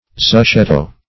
zuchetto - definition of zuchetto - synonyms, pronunciation, spelling from Free Dictionary
Search Result for " zuchetto" : The Collaborative International Dictionary of English v.0.48: Zuchetto \Zu*chet"to\, n. [It. zucchetto.]